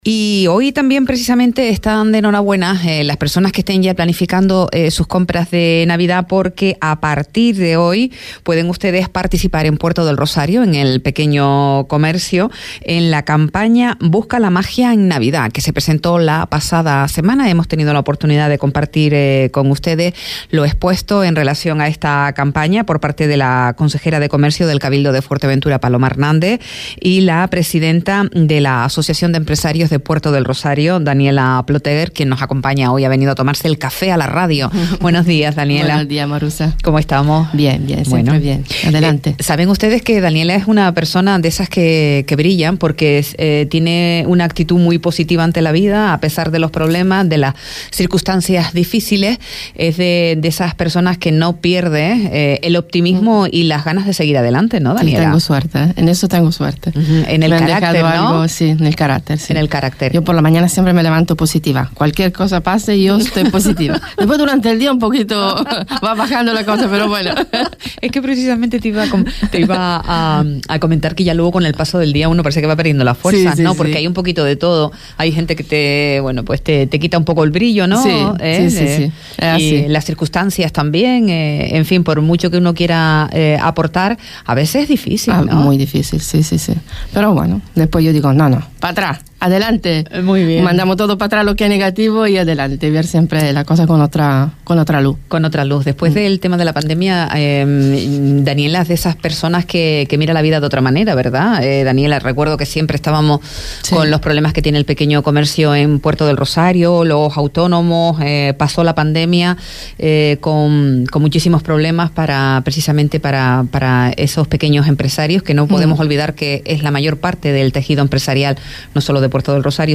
Entrevistas A Primera Hora